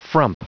Prononciation du mot frump en anglais (fichier audio)
Prononciation du mot : frump